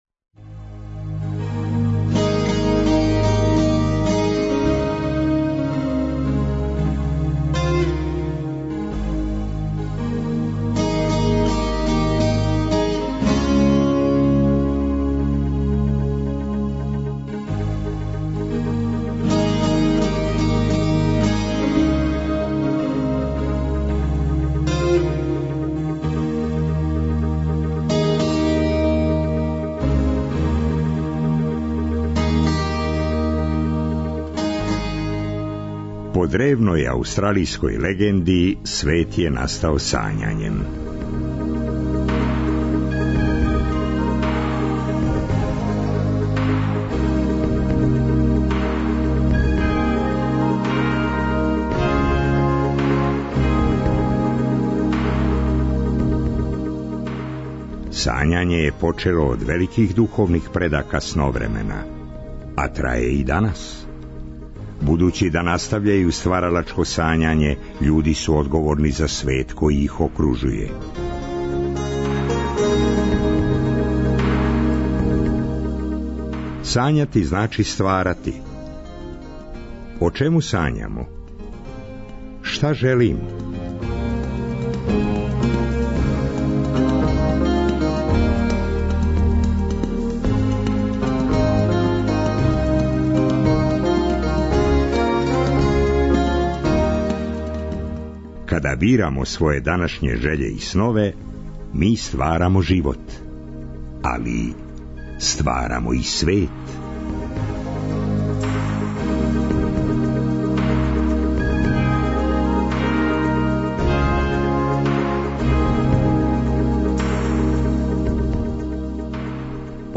На пароброду Жупа дружили смо се са младим битбоксерима из Немачке, а испред Дома омладине Београда слушали смо хипхопере, учеснике радионице Next Level.